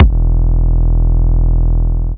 Index of /Antidote Advent/Drums - 808 Kicks
808 Kicks 06 E.wav